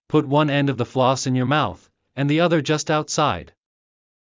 ﾌﾟｯﾄ ﾜﾝ ｴﾝﾄﾞ ｵﾌﾞ ｻﾞ ﾌﾛｽ ｲﾝ ﾕｱ ﾏｳｽ ｴﾝﾄﾞ ｼﾞ ｱｻﾞｰ ｼﾞｬｽﾄ ｱｵｳﾄｻｲﾄﾞ